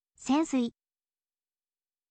sensui